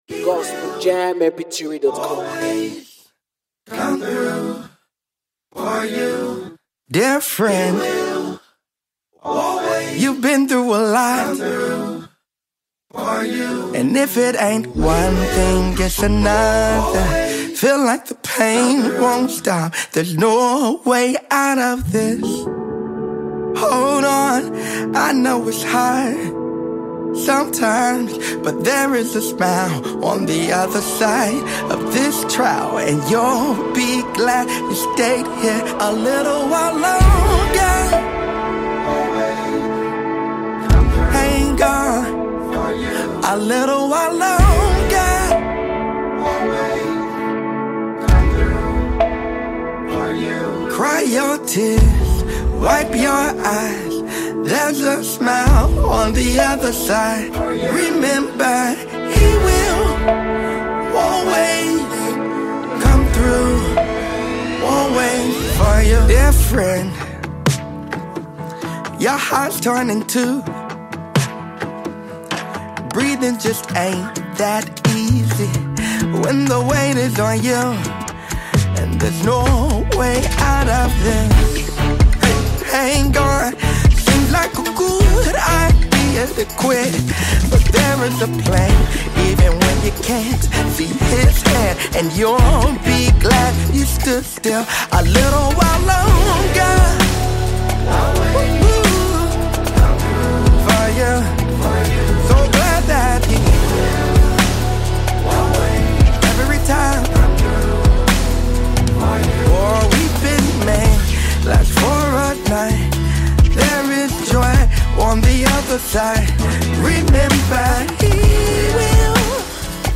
Genre: Contemporary Gospel / Christian Hip-Hop